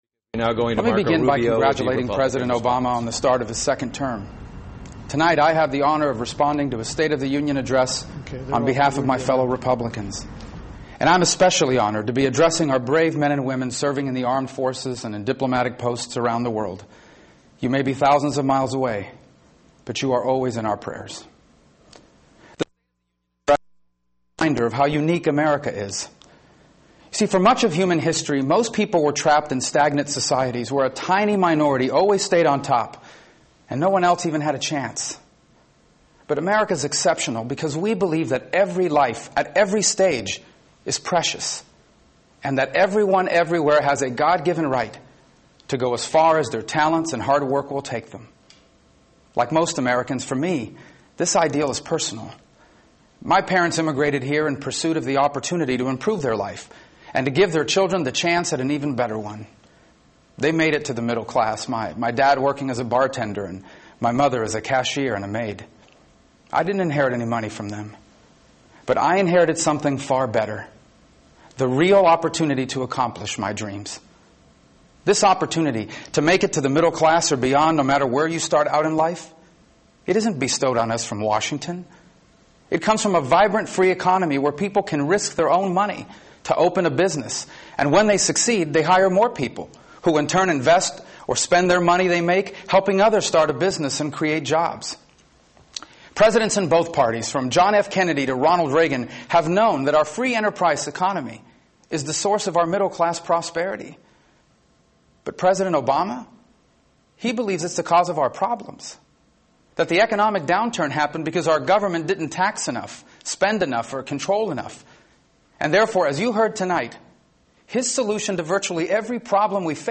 Senator Marco Rubio with The Republican Response to President Obamas State Of The Union Address